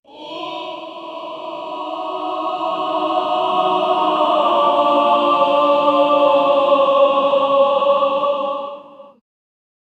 A choir of angels scary
a-choir-of-angels-scary-wgqmtgpg.wav